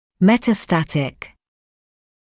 [ˌmetə’stætɪk][metə’stætɪk]метастатический, относящийся к метастазу